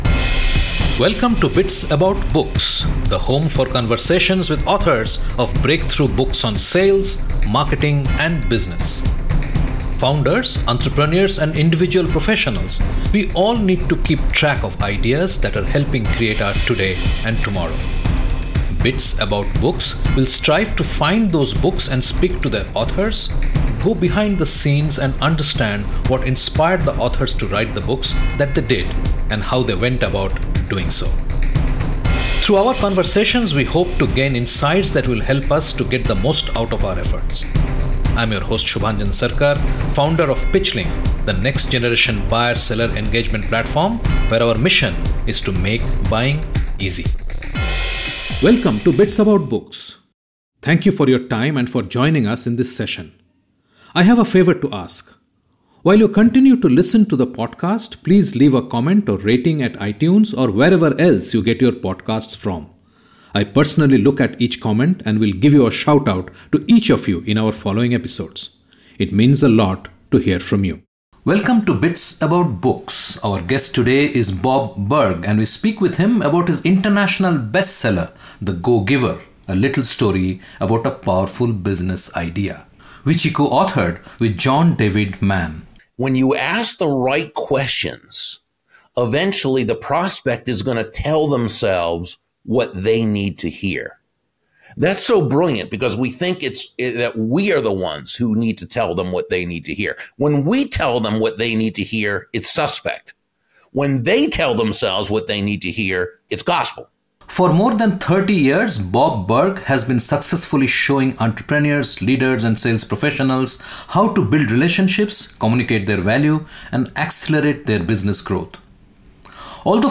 In Conversation with Bob Burg, Author, "The Go-Giver".